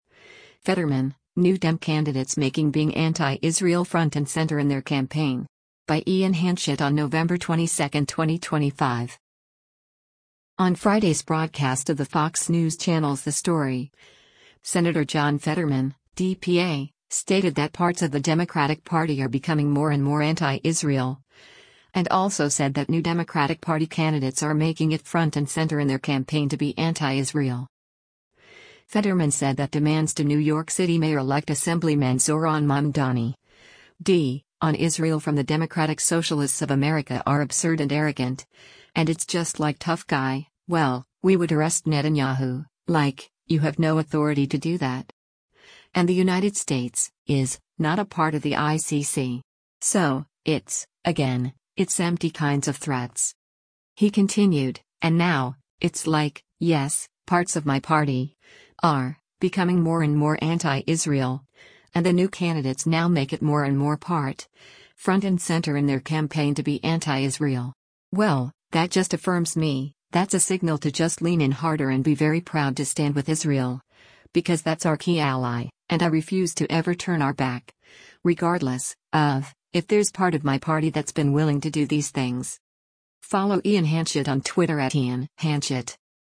On Friday’s broadcast of the Fox News Channel’s “The Story,” Sen. John Fetterman (D-PA) stated that parts of the Democratic Party are “becoming more and more anti-Israel,” and also said that new Democratic Party candidates are making it “front and center in their campaign to be anti-Israel.”